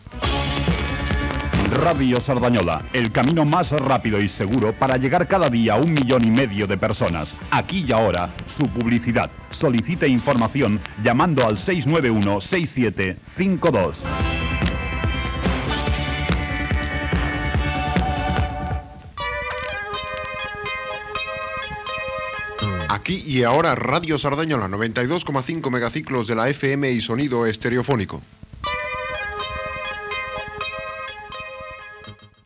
2e07a6df2fd1ebd50752be085ff83c057ceb183c.mp3 Títol Ràdio Cerdanyola Emissora Ràdio Cerdanyola Titularitat Tercer sector Tercer sector Comercial Descripció Publicitat per anunciar-se a l'emissora, indicatiu i presentació d'un tema musical.